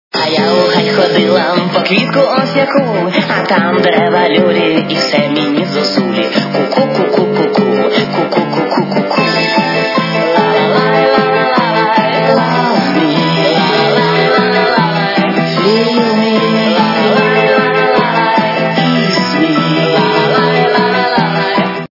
украинская эстрада
качество понижено и присутствуют гудки